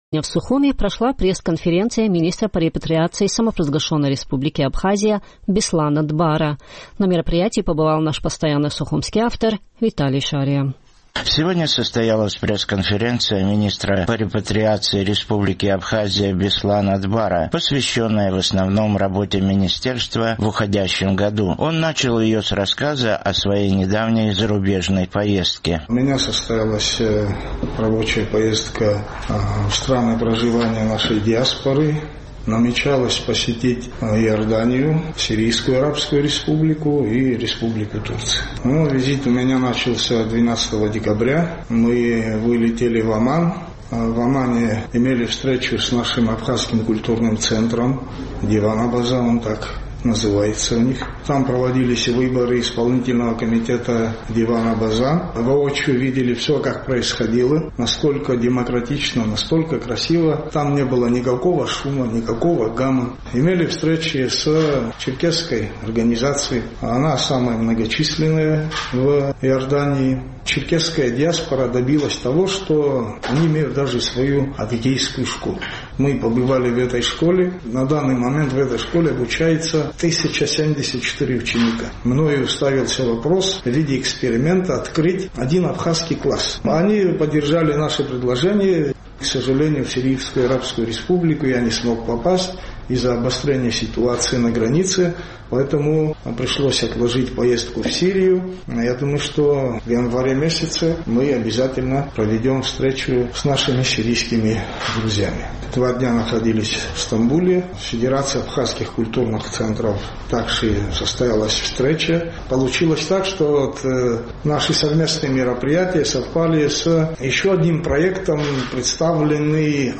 Сегодня состоялась пресс-конференция министра по репатриации Республики Абхазия Беслана Дбара, посвященная в основном работе министерства в уходящем году.